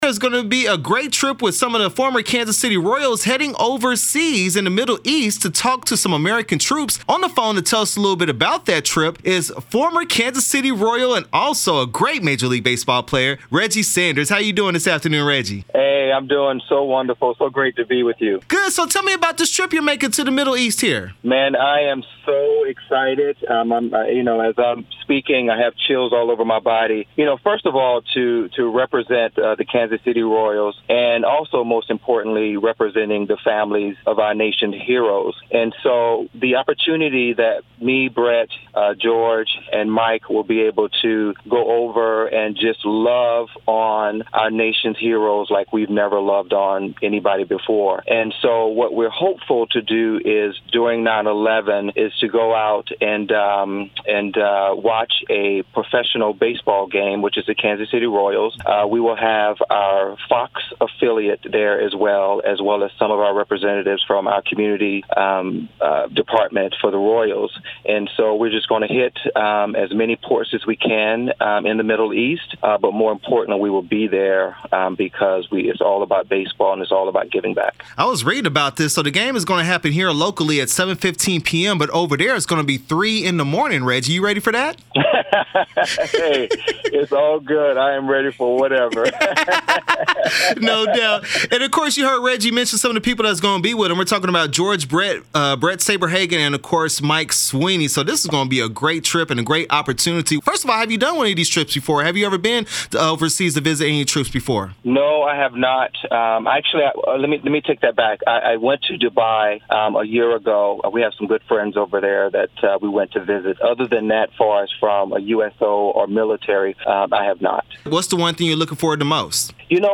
Reggie Sanders/ “This One’s For You” interview 8/28/18
Thanks to former KC Royals player Reggie Sanders for calling the show to talk about his upcoming trip with other Royals greats to the Middle East to visit American troops including those deployed from the Missouri National Gaurd in partnership with the USO and Fox Sports KC. This will be part of a telecast happening on Sept. 11 when the Royals play the Chicago White Sox.